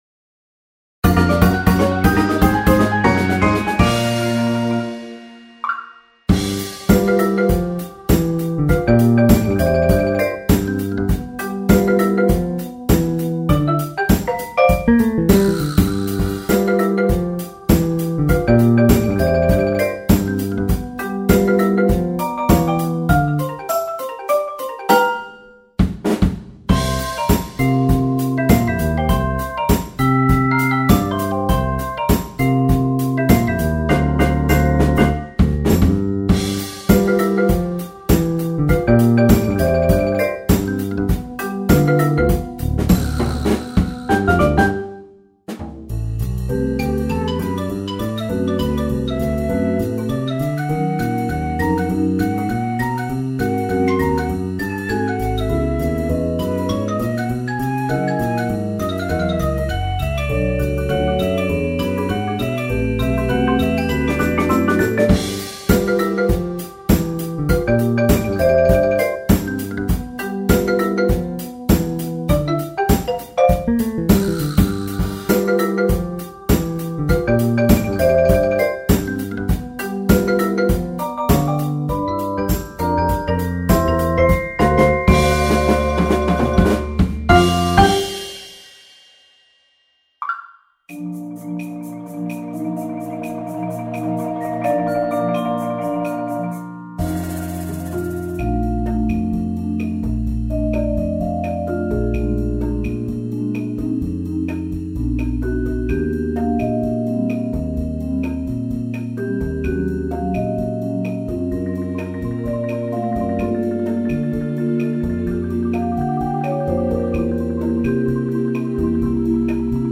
Genre: Percussion Ensemble
# of Players: 8-11
Player 1: Glockenspiel
Player 2: Vibraphone
Player 4: Xylophone, Bongos
Player 5: Marimba (4-octave)
Player 9: Drum Set
Player 10: Bass Guitar
Player 11*: MainStage Synth